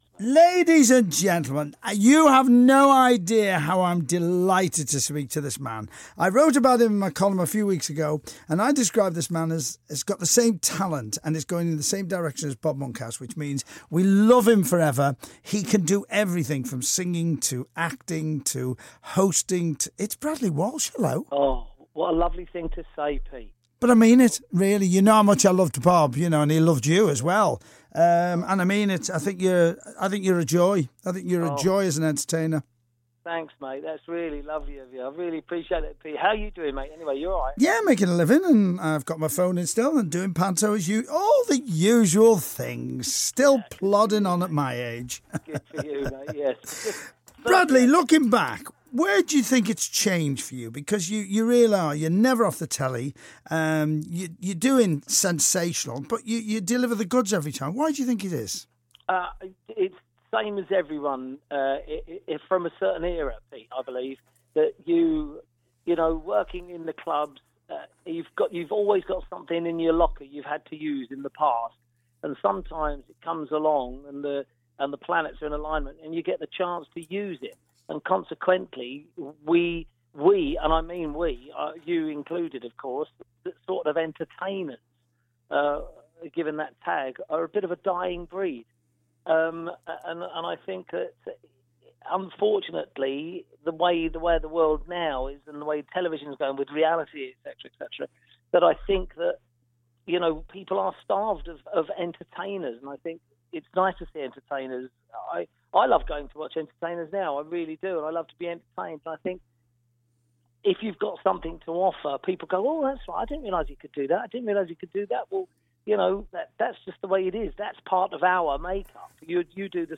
Bradley Walsh chats to Pete Price on Radio City 2 & Radio City Talk